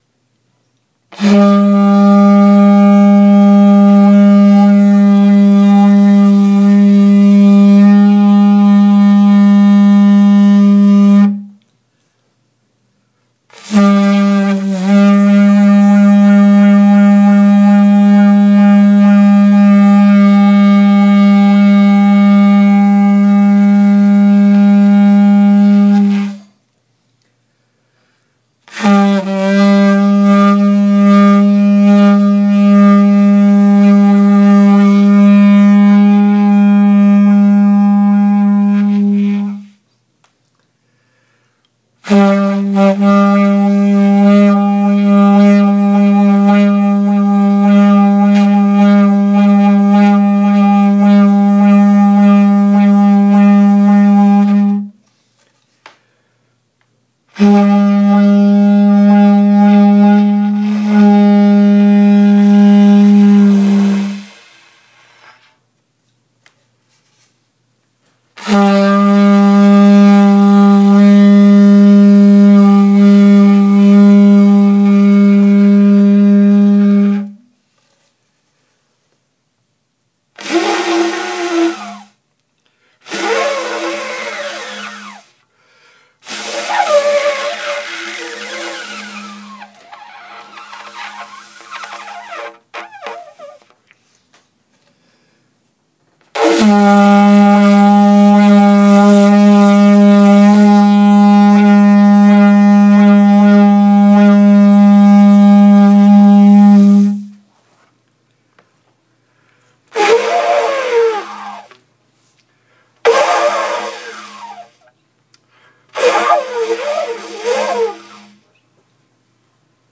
Practicing the Didge Again